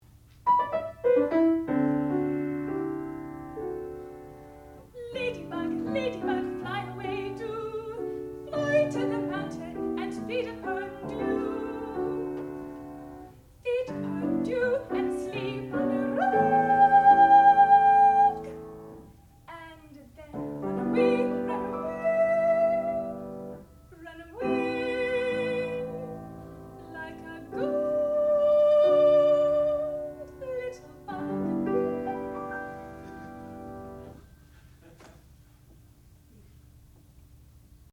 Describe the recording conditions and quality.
Student Recital